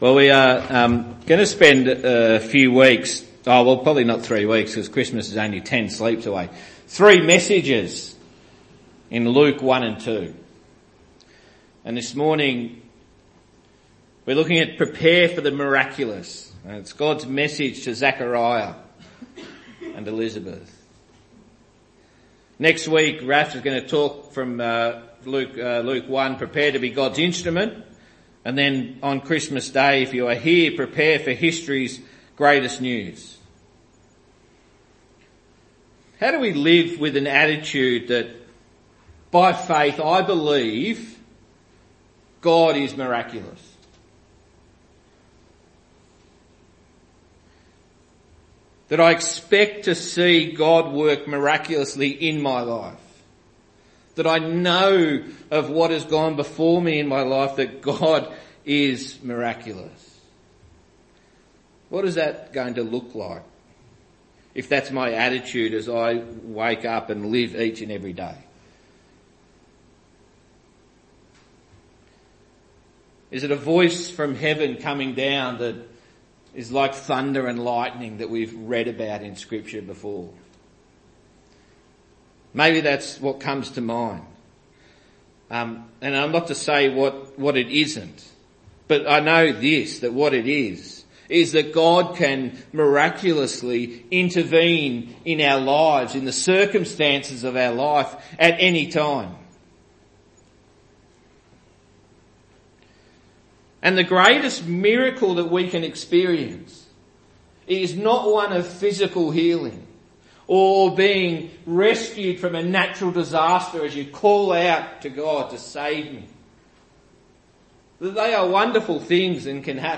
Messages from Monty's service's.